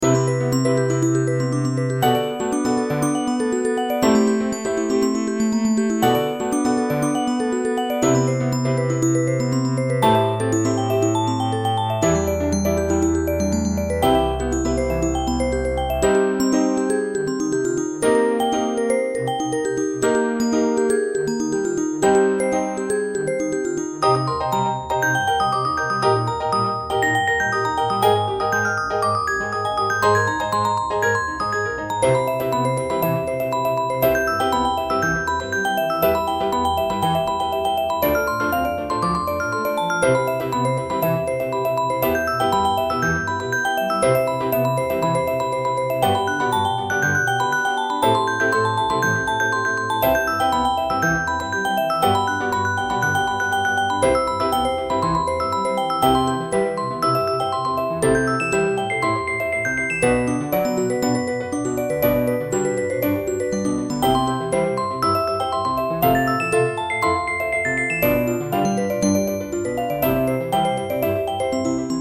ティンクルベル、ピアノ、コントラバス